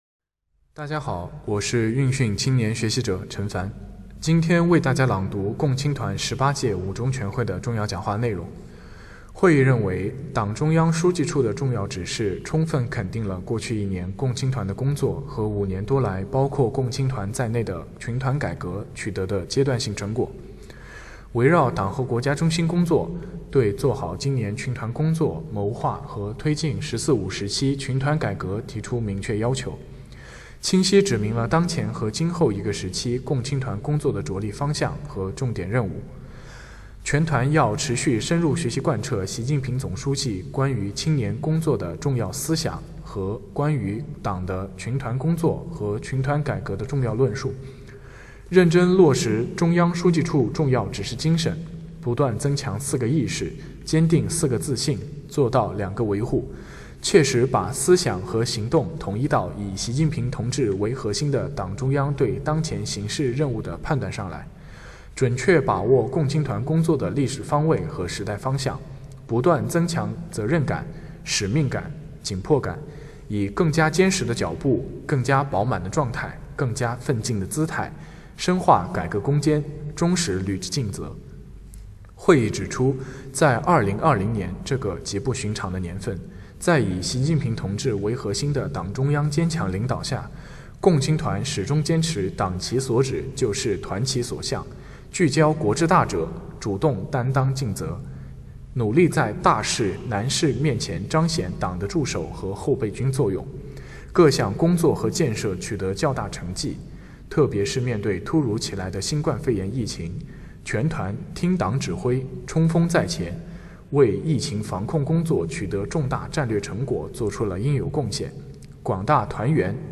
共青团十八届五中全会·朗读内容